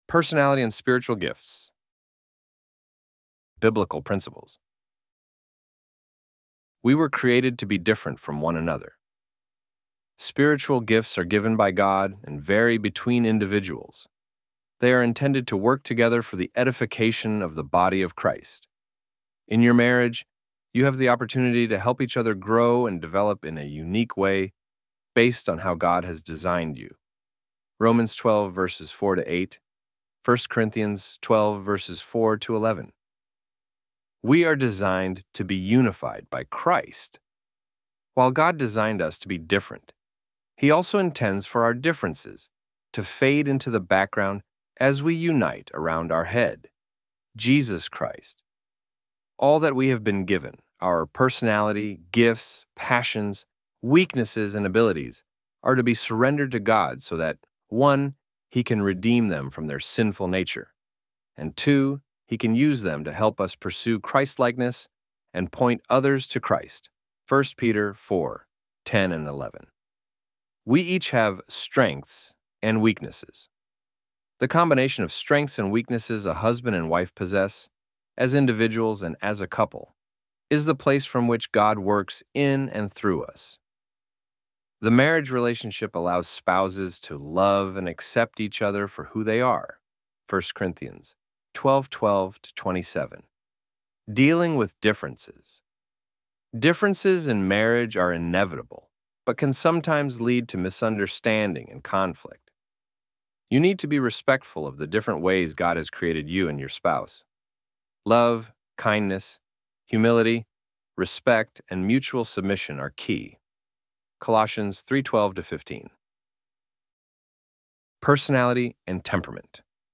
ElevenLabs_Personality__Spiritual_Gifts_-_GSM.mp3